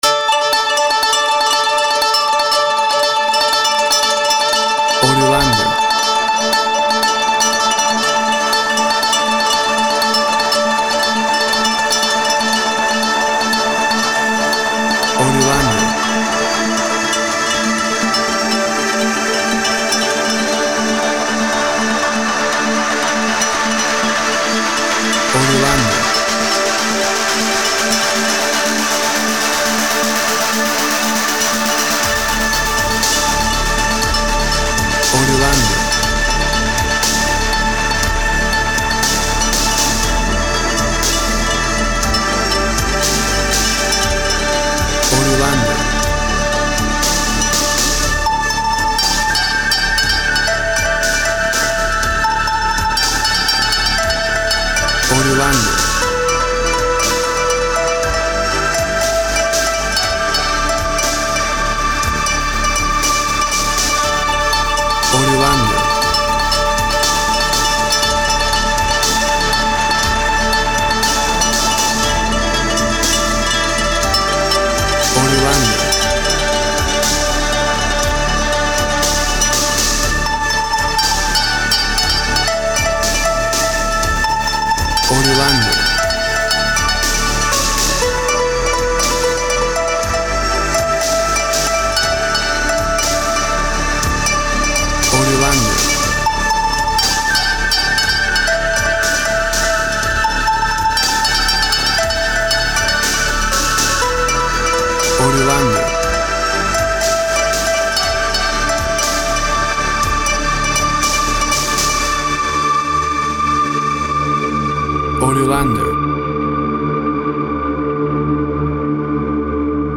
An uplifting piece featuring a moon guitar.
Tempo (BPM) 60